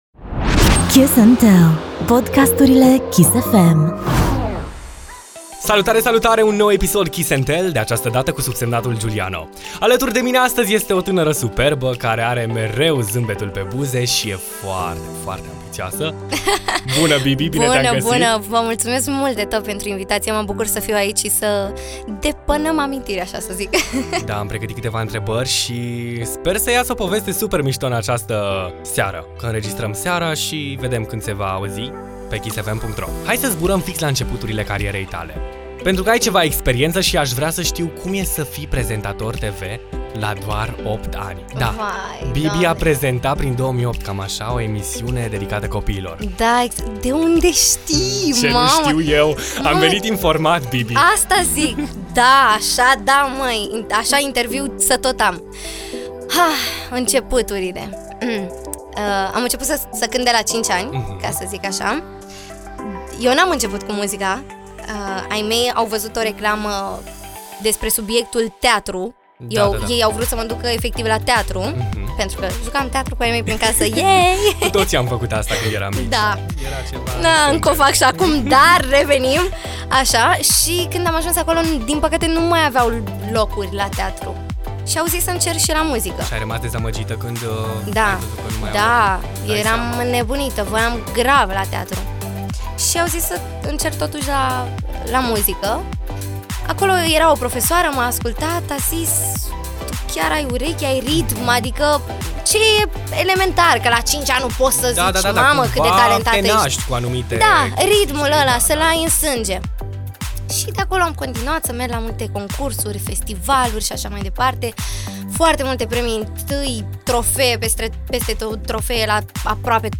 Interviurile Kiss FM